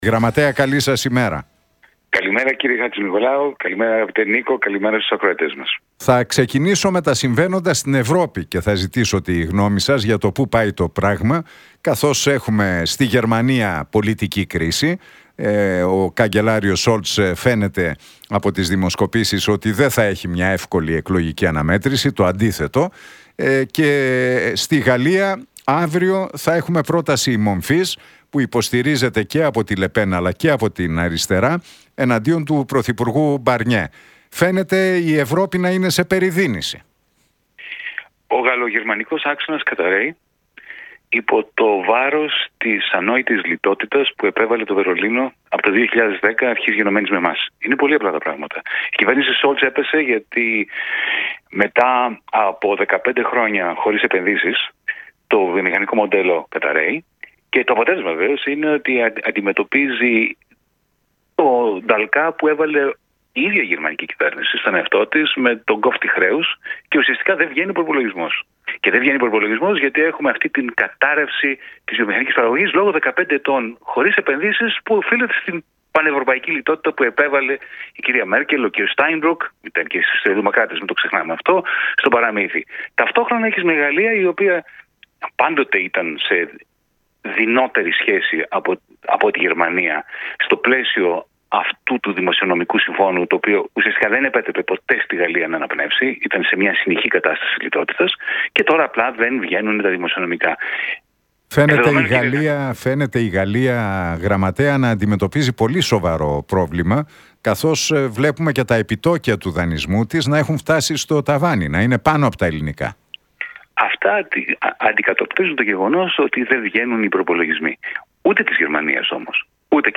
Για τις εξελίξεις σε Γαλλία και Γερμανία, την κατάσταση της ελληνικής οικονομίας και την φοροδιαφυγή μίλησε ο γενικός γραμματέας του Μέρα25, Γιάνης Βαρουφάκης στον Νίκο Χατζηνικολάου και την συχνότητα του Realfm 97,8.